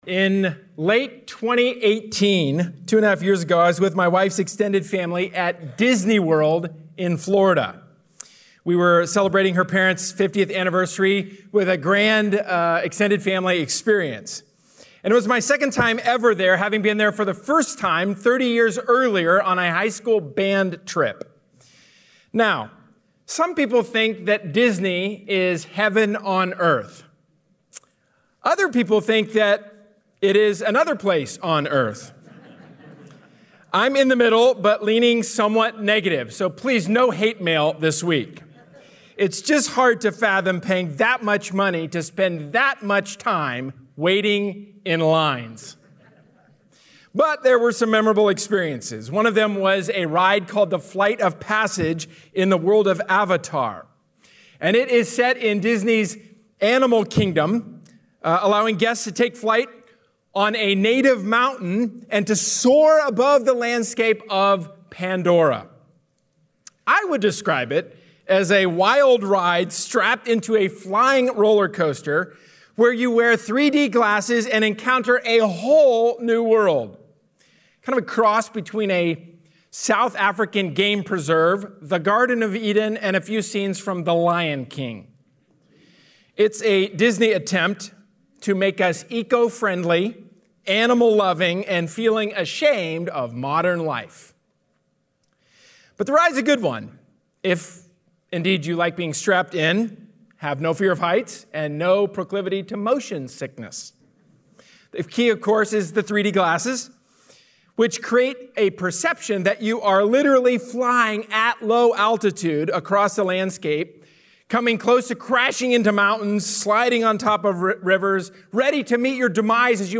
A sermon from the series "James: Faith/Works."